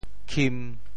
钦（欽） 部首拼音 部首 钅 总笔划 9 部外笔划 4 普通话 qīn 潮州发音 潮州 kim1 文 潮阳 kim1 文 澄海 king1 文 揭阳 kim1 文 饶平 kim1 文 汕头 kim1 文 中文解释 潮州 kim1 文 对应普通话: qīn ①恭敬，敬重：～爱 | ～佩 | ～仰。